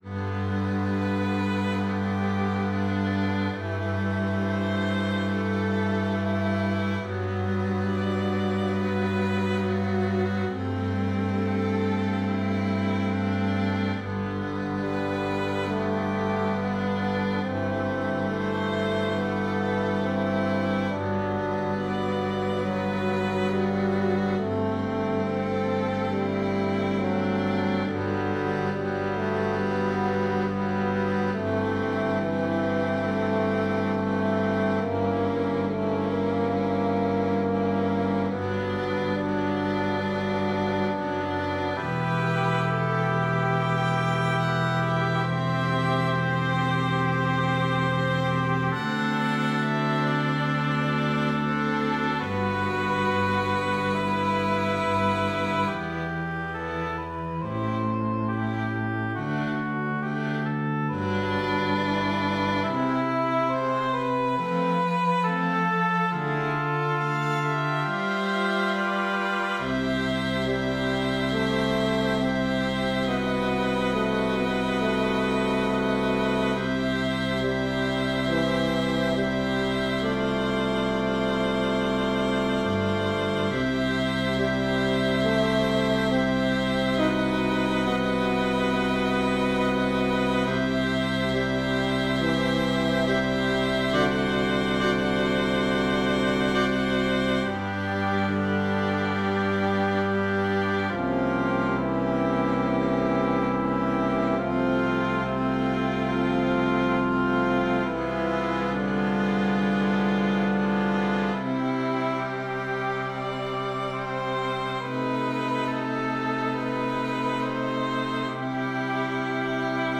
Pour orchestre à cordes, 2 hautbois et 2 cors